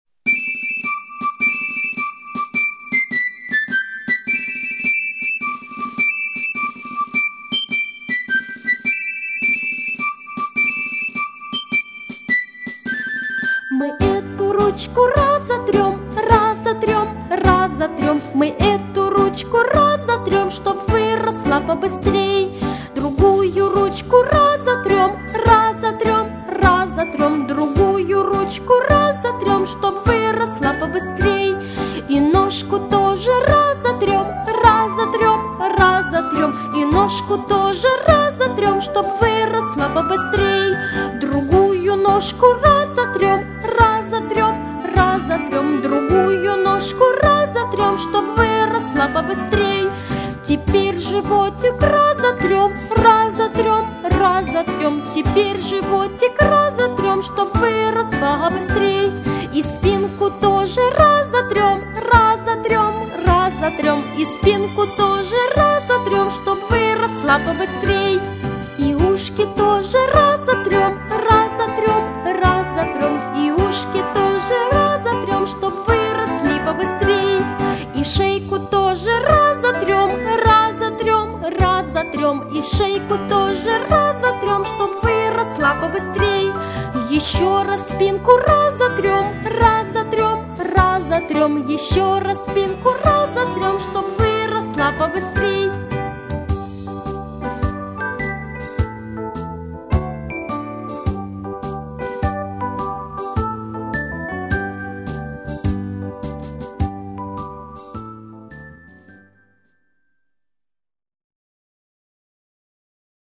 Детские песенки для домашнего массажа своими руками